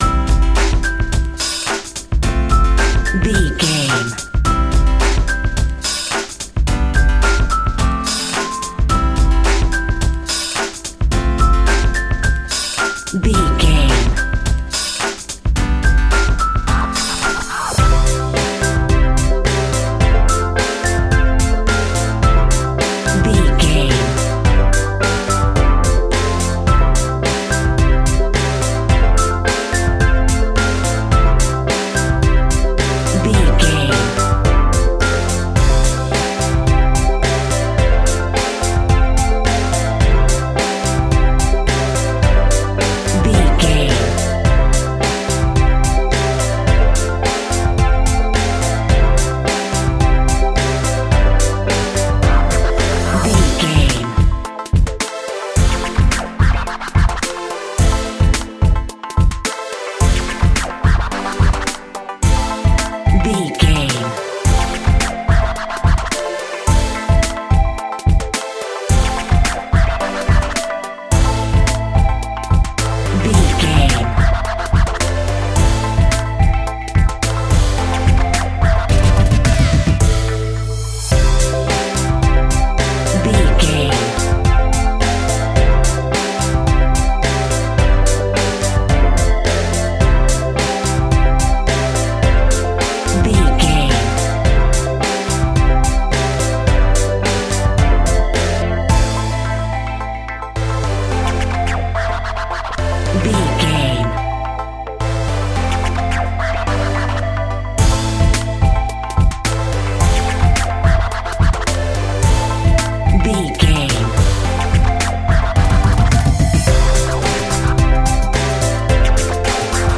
Uplifting
Ionian/Major
childrens music
instrumentals
fun
childlike
cute
happy
kids piano